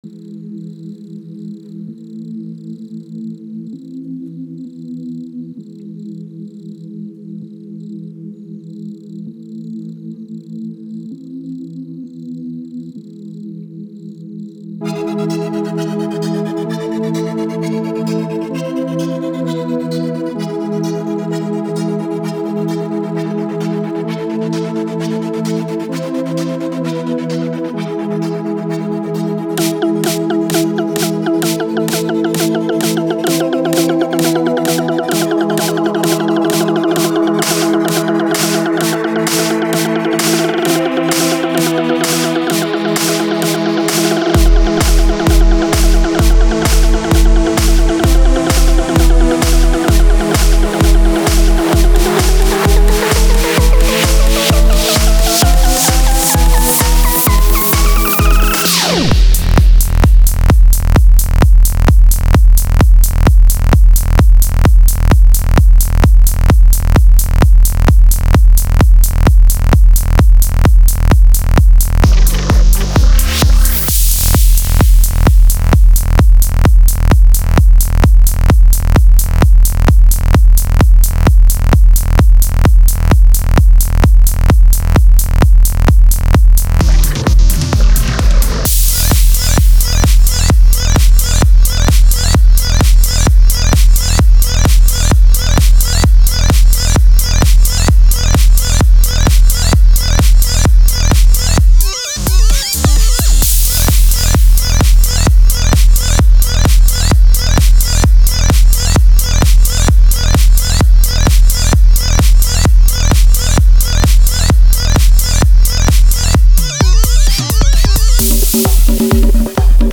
Jupiter (EDM-ish)